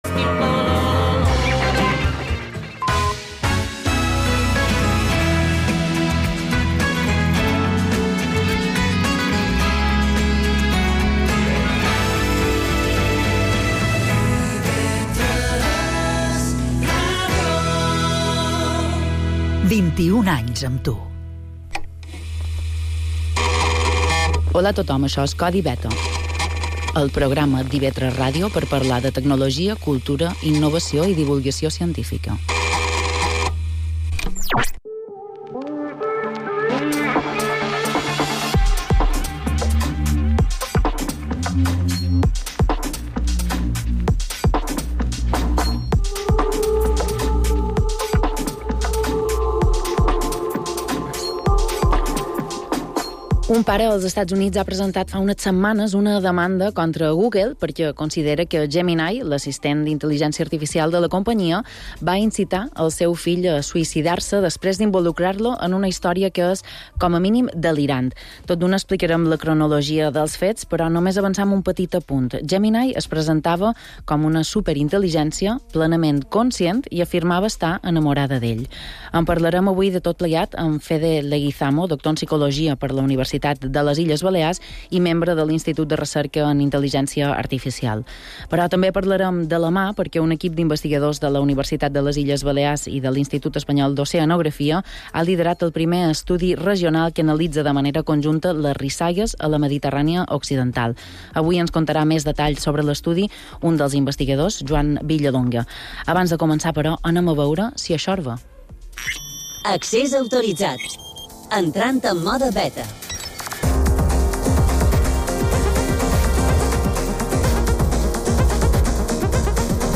-Emissió a IB3 Ràdio: dissabtes de 23 a 00h.